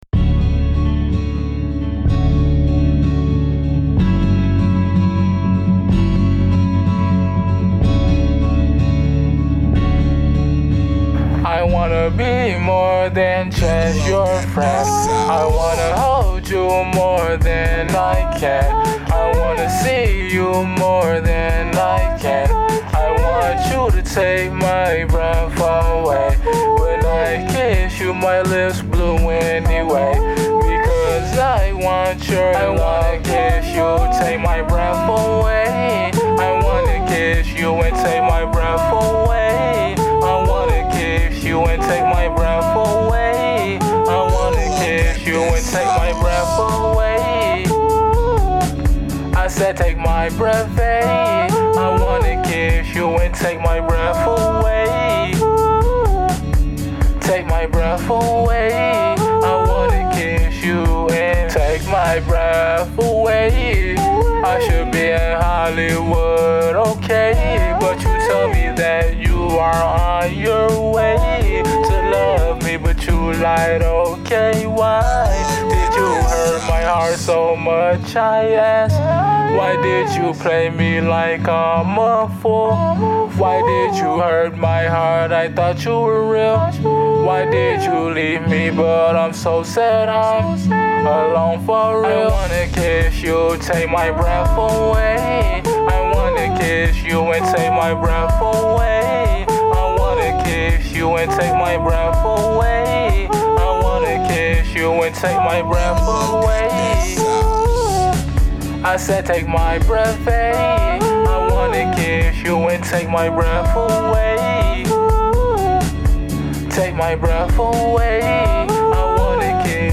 ALETERNATIVE ROCK & INDIE ROCK